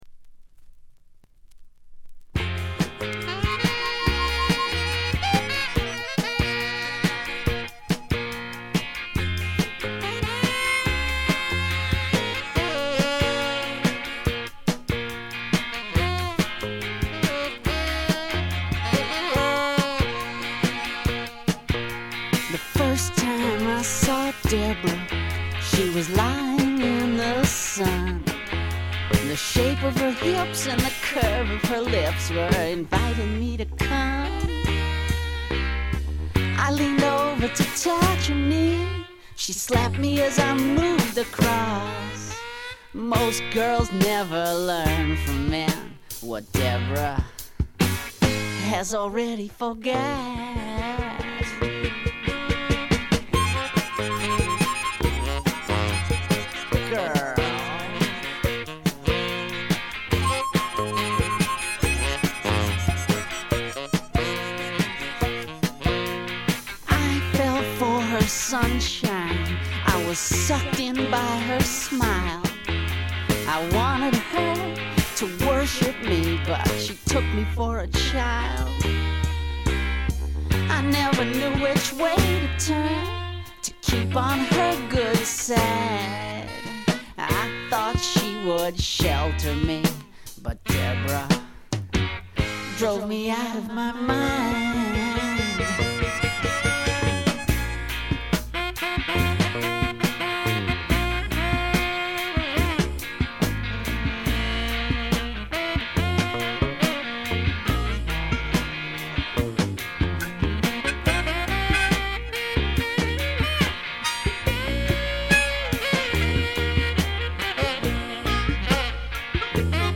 気付いたのはこれぐらい、ほとんどノイズ感無し。
試聴曲は現品からの取り込み音源です。